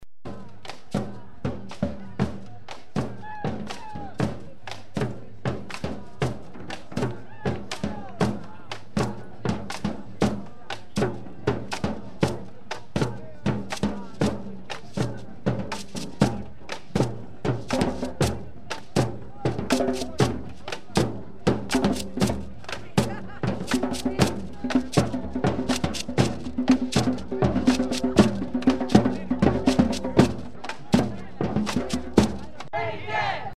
SF Antiwar Demo Sound 2
Quite a few drummers showed up. Here's one group's licks (:34)
drums2.mp3